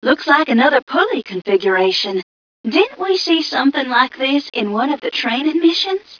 mission_voice_m1ca021.wav